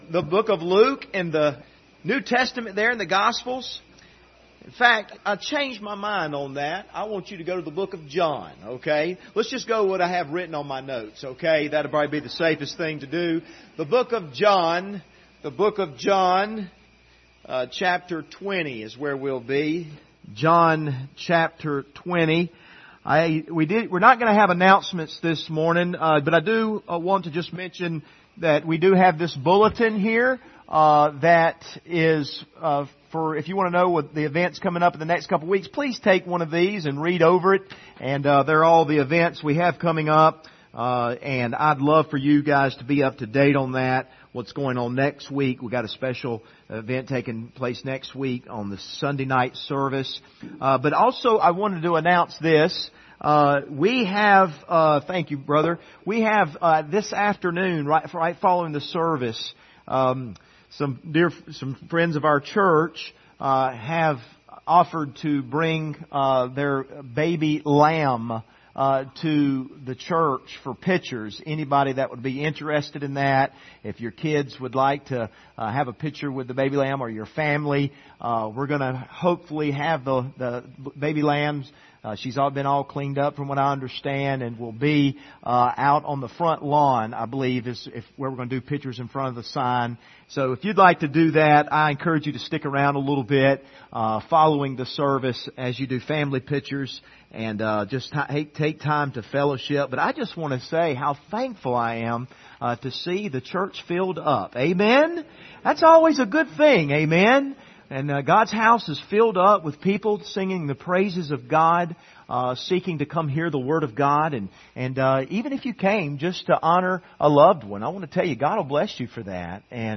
Passage: John 20:19-29 Service Type: Sunday Morning View the video on Facebook « The Reality of Hell Protectors of the Peace of God